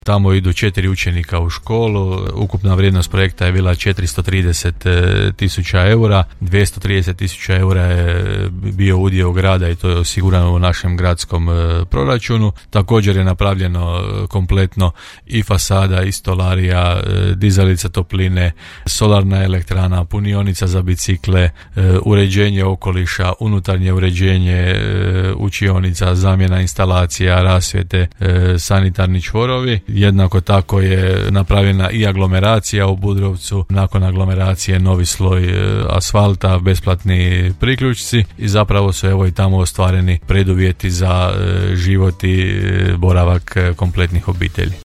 -kazao je ranije za Podravski radio gradonačelnik Janči.